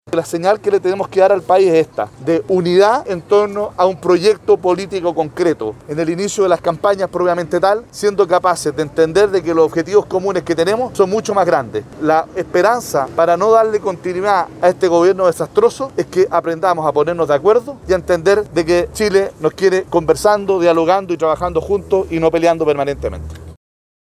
El objetivo común es la necesidad de mantener la unidad durante el proceso, según apuntó el presidente de la Democracia Cristiana, Fuad Chahín, quien dijo que no se pueden perpetuar las diferencias entre partidos, si se quiere un cambio real.